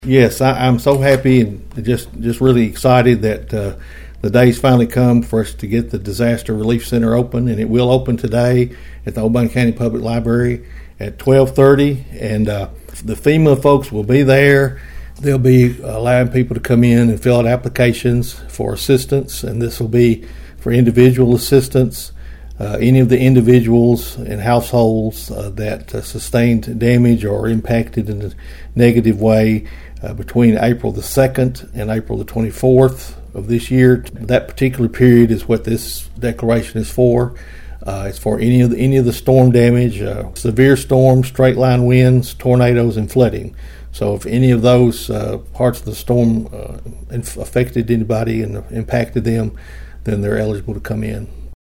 County Mayor Steve Carr told Thunderbolt News about the opening of a FEMA Disaster Recovery Center in Union City.(AUDIO)